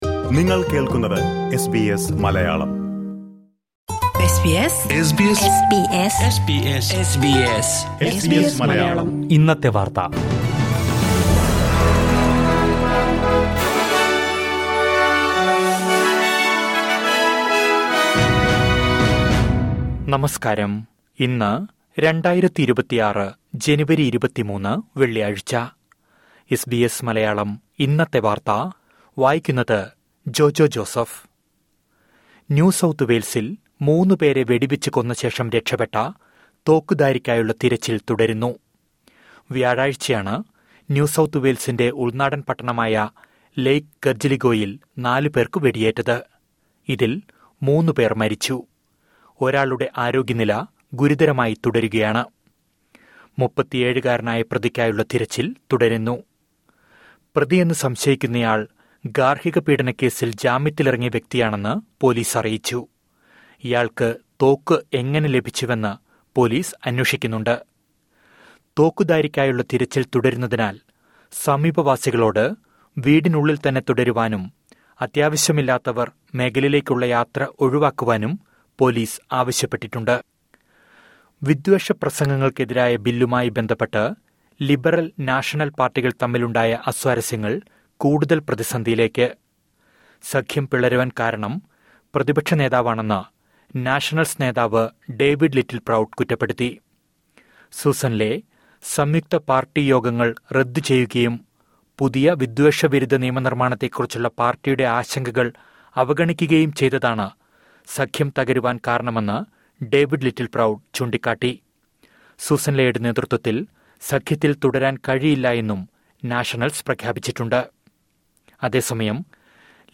2026 ജനുവരി 23ലെ ഓസ്ട്രേലിയയിലെ ഏറ്റവും പ്രധാന വാർത്തകൾ കേൾക്കാം...